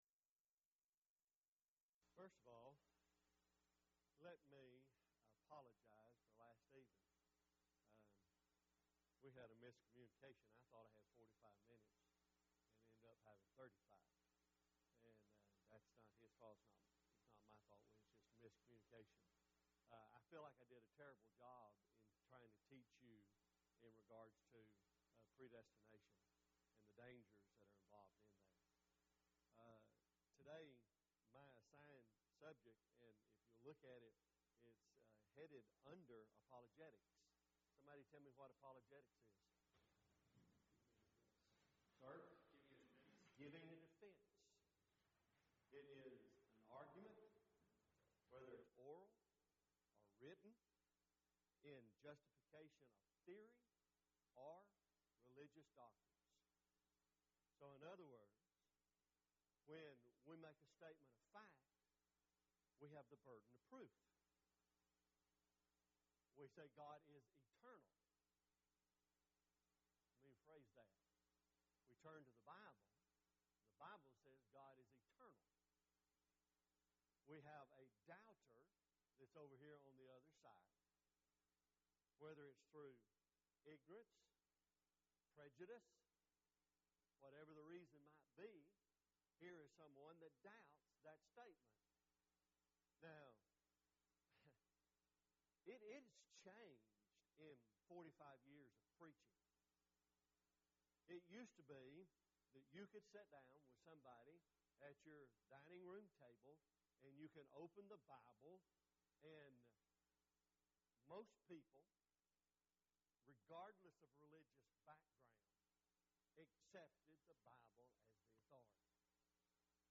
Event: 4th Annual Men's Development Conference
lecture